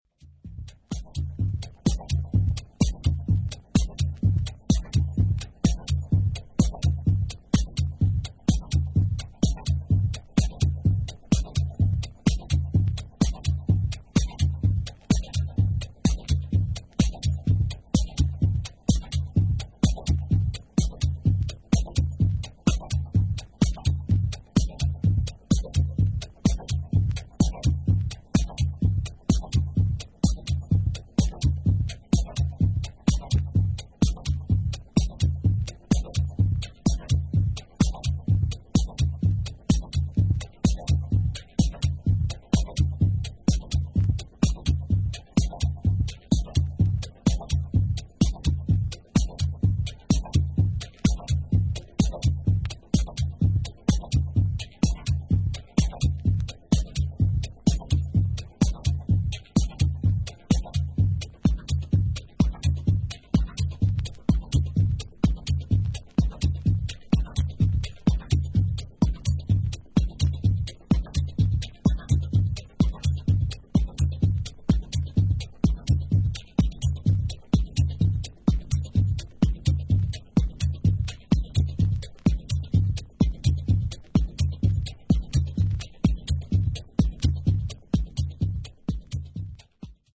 迫力のあるベースを軸にしたシーケンスで、永遠に続くかのように錯覚するグルーヴを保ったダブテクノ二曲を収録した本作。